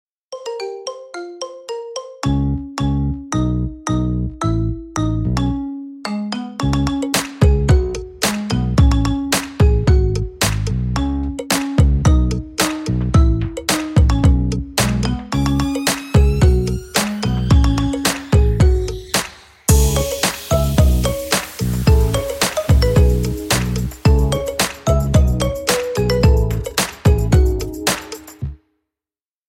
Categoria Marimba Remix